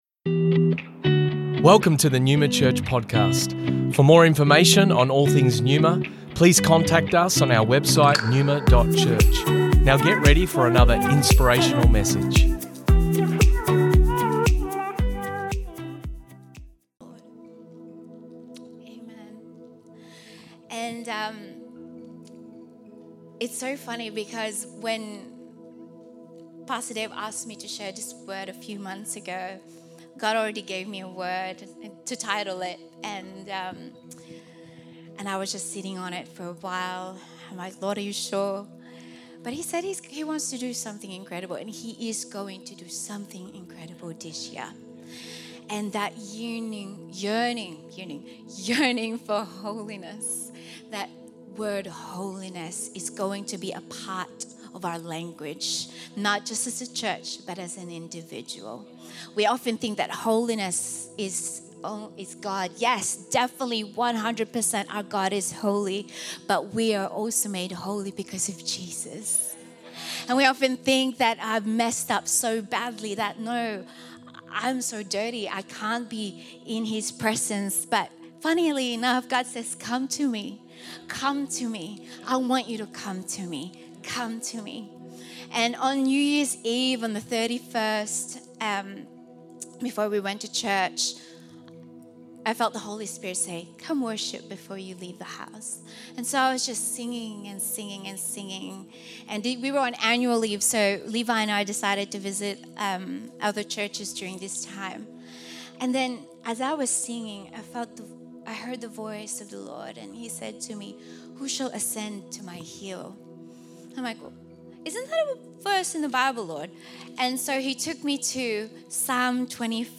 Originally recorded at Neuma Melbourne West Sunday January 28th 2023